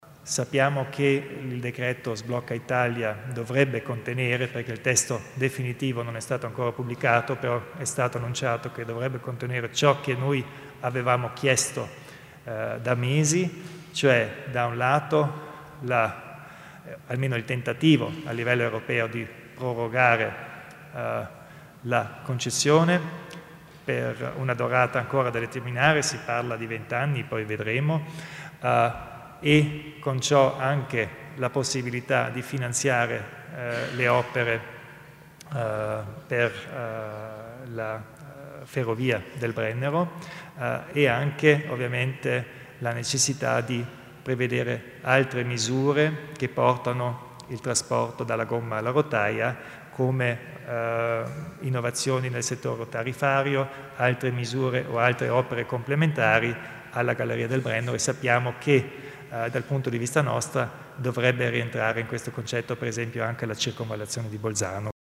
Il Presidente Kompatscher spiega il futuro della concessione dell'A22